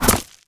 bullet_impact_gravel_06.wav